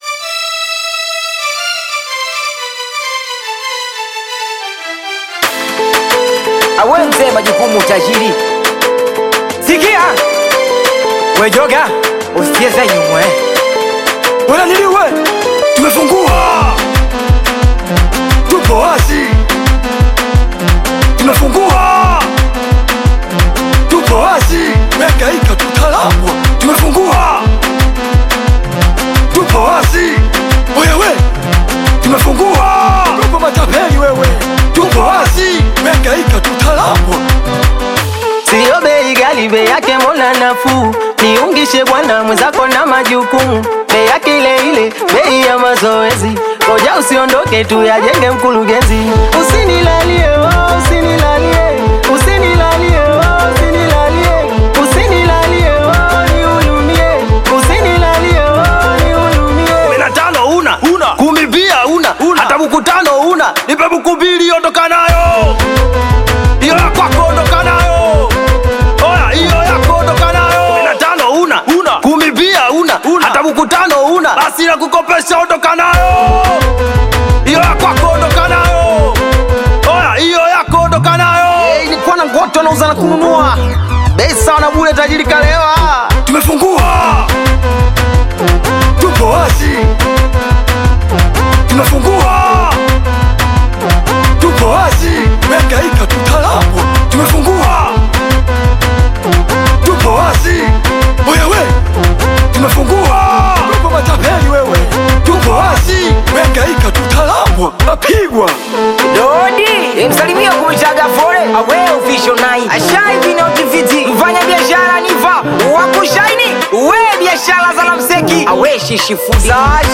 Singeli music track
Tanzanian Bongo Flava
Singeli song